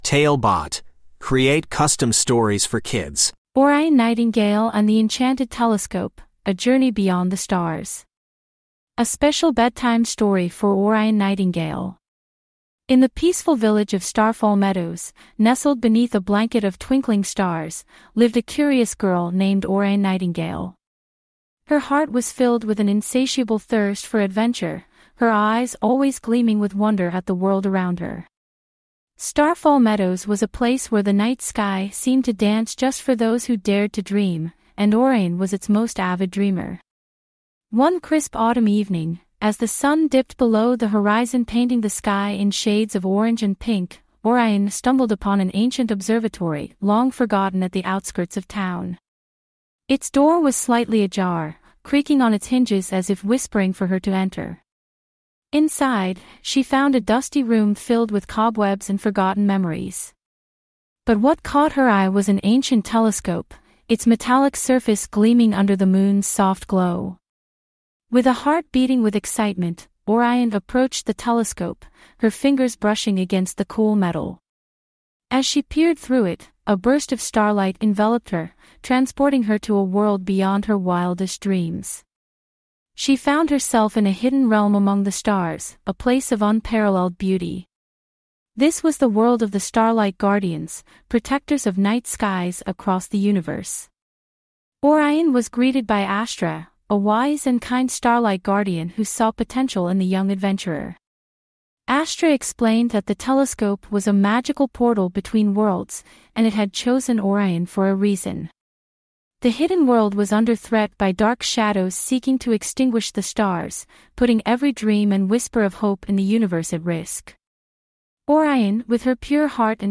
Orion Nightingale and the Enchanted Telescope: A Journey Beyond the Stars - TaleBot Bedtime Stories